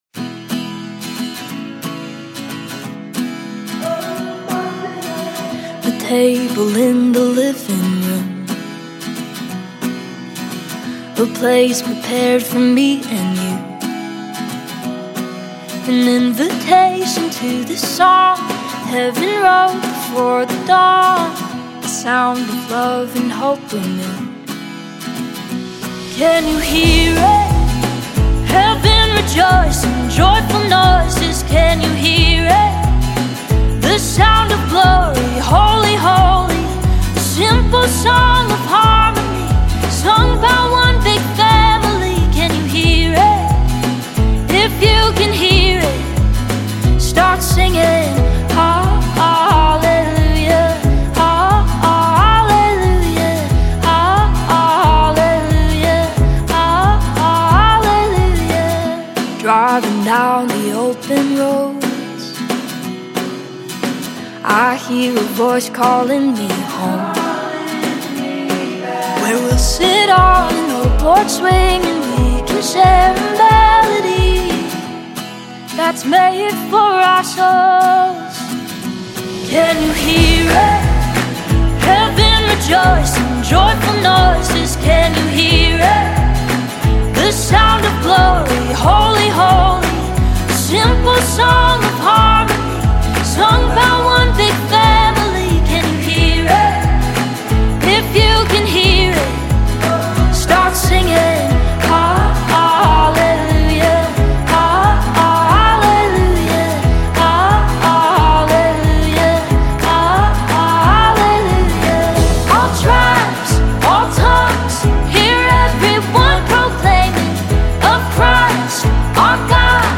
Gospel/Inspirational